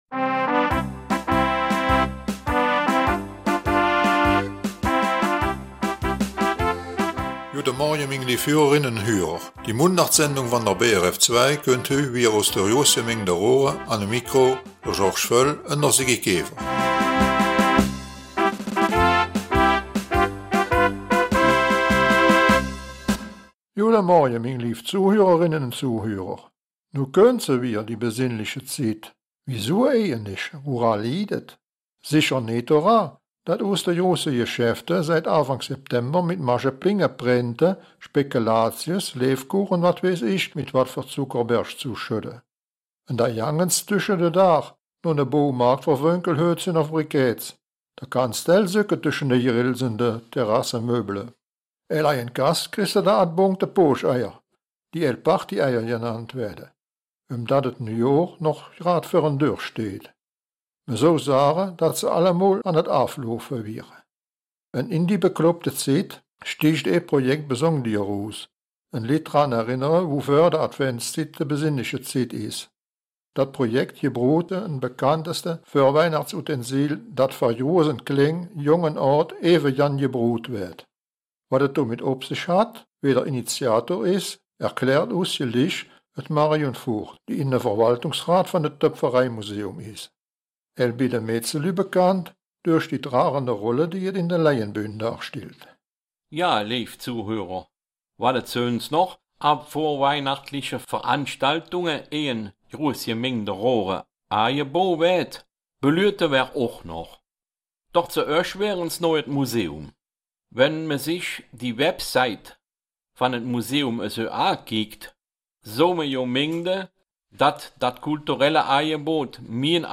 Raerener Mundart - 9.